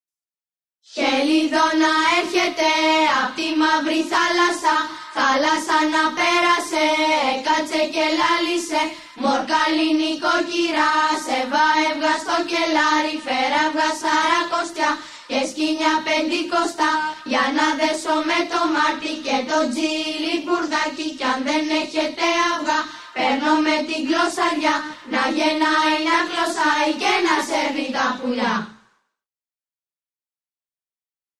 Πρόκειται για τα λεγόμενα «χελιδονίσματα» τα ανοιξιάτικα κάλαντα. Το τέλος του Χειμώνα και τον ερχομό των χελιδονιών γιόρταζαν τα παιδιά από την αρχαιότητα με τα “χελιδονίσματα”.